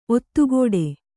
♪ ottugōḍe